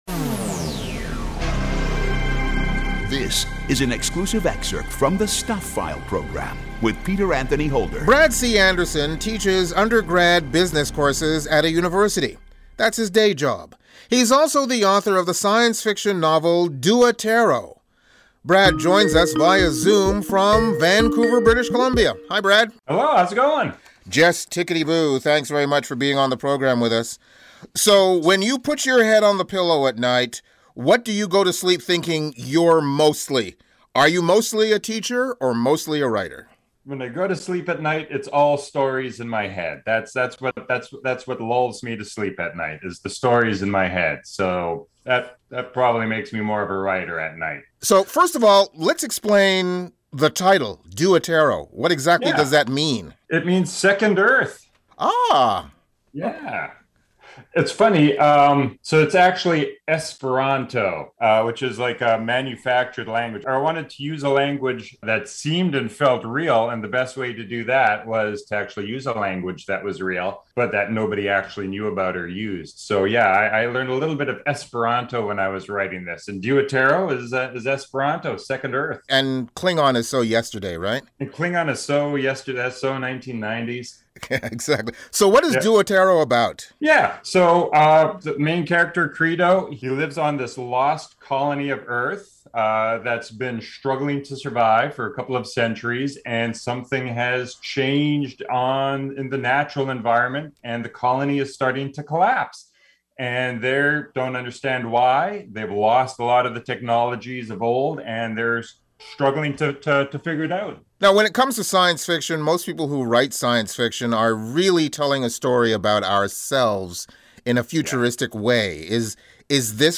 Stuph File Interview